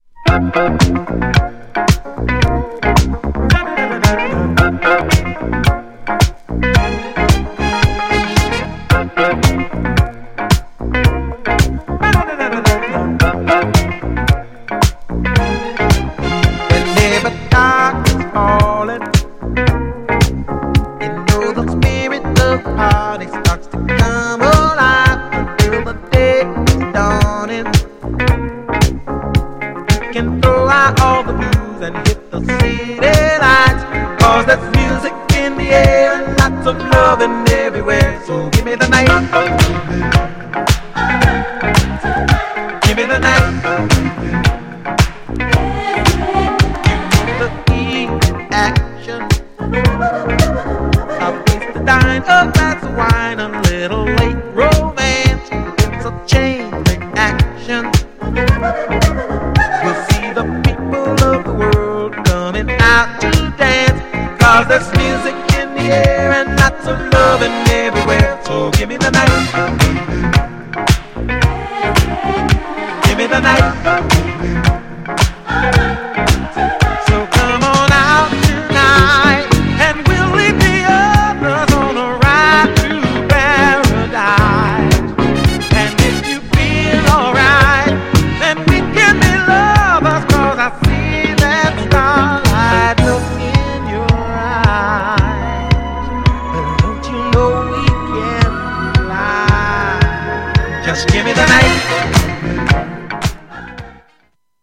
GENRE Dance Classic
BPM 76〜80BPM